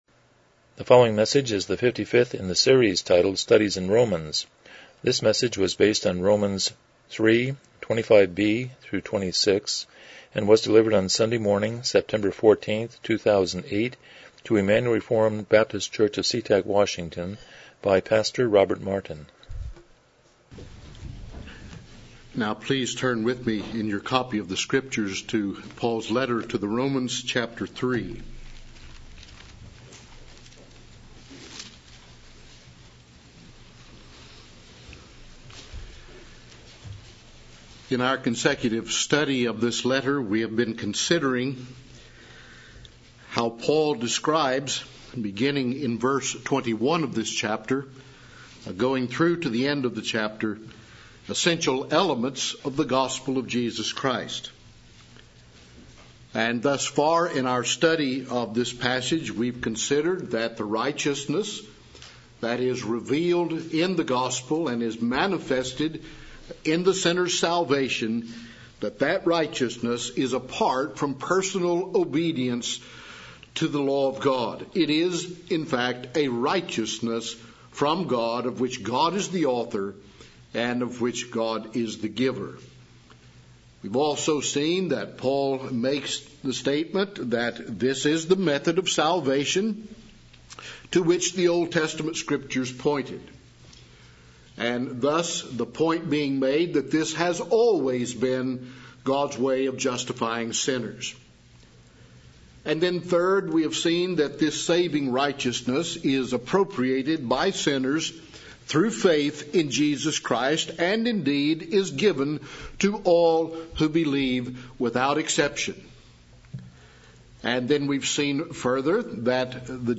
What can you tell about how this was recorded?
Passage: Romans 3:25-26 Service Type: Morning Worship